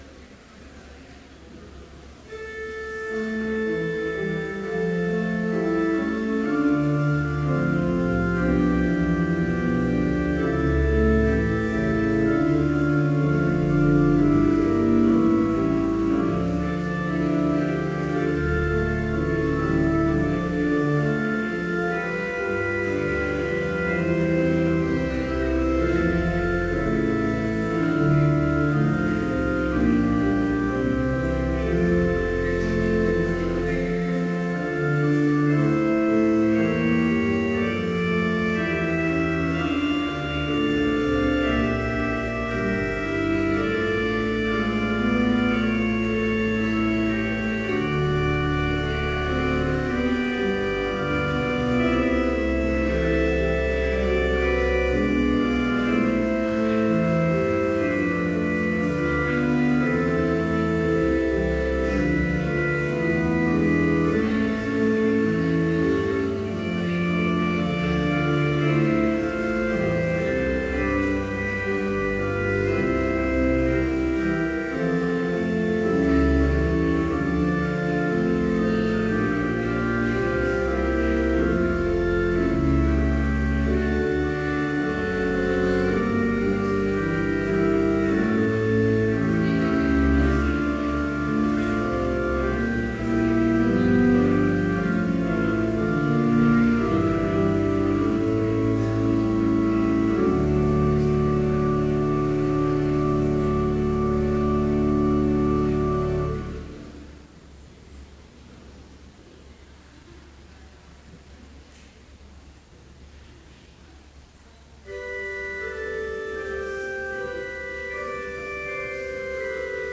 Complete service audio for Chapel - May 14, 2015